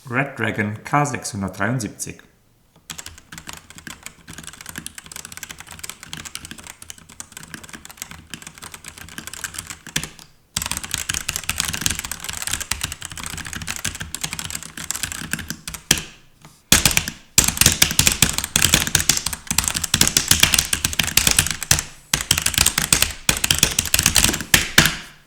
Akustisch neigen die Modelle zudem zu einem klapprigen Klackern.
Ein guter Teil des Kunststoff-artigen Klackerns und Klickerns, das schon beim Antippen der Taster entsteht, produzieren sie und ihre dünnen Kappen.
Das klingt ziemlich schrecklich und verlangt daher nach einer Einordnung.
Die Redragon liefert ein gleichmäßigeres, tieferes Klangbild, das sich besser ausblenden lässt.